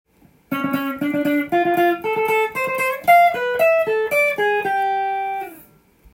譜面通り弾いてみました
②のフレーズもウェス独特の定番リズム系フレーズになります。